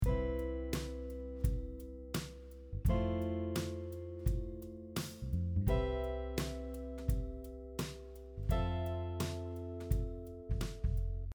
For this next example which includes another chord, I am thinking of the chord tones for F Major 7 to play a smooth transition when switching between chords.
drop 2 chords over a simple chord progression application example